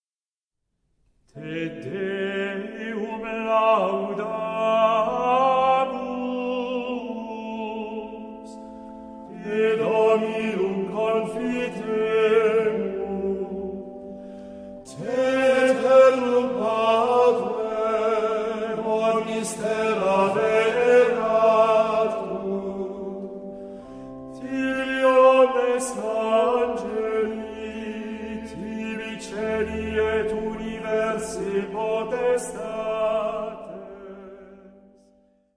Il canto gregoriano, espressione più antica della Chiesa di Roma, presenta un vastissimo repertorio di inni, salmodie e sequenze.